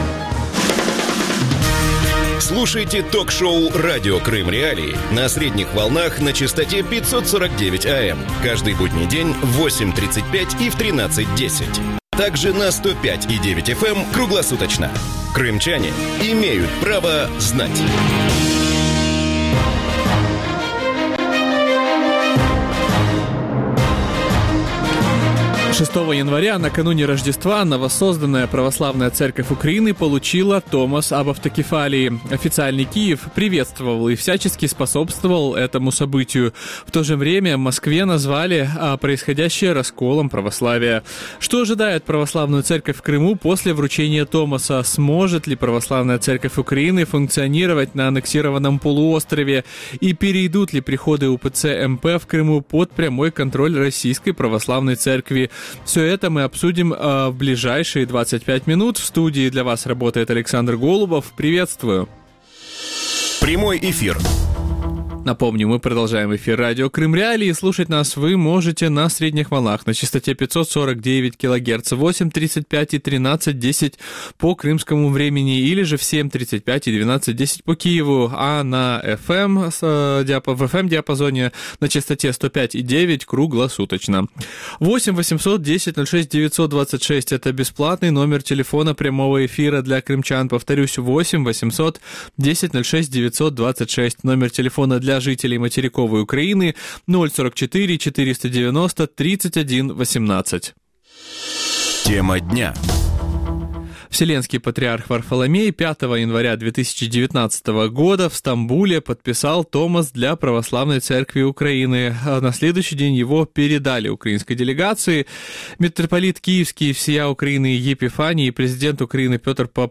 Что ожидает Украинскую православную церковь в Крыму после вручения томоса? Сможет ли Православная церковь Украины функционировать на аннексированном полуострове? И перейдут ли приходы УПЦ МП в Крыму под прямой контроль Российской православной церкви? Гости эфира: Климент, архиепископ Симферопольский и Крымский УПЦ Киевского патриархата;